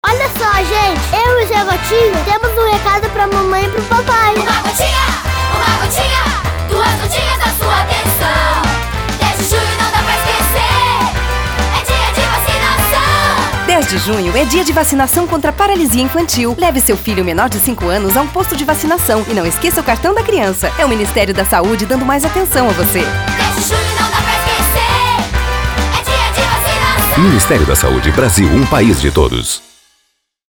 Ze Gotinha Spot.mp3